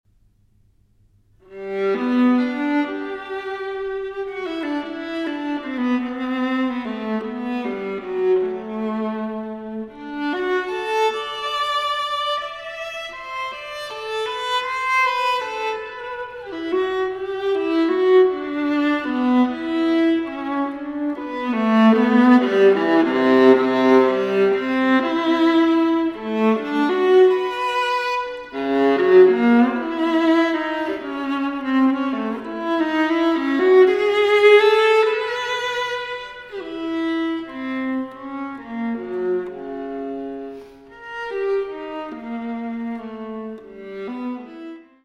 for Viola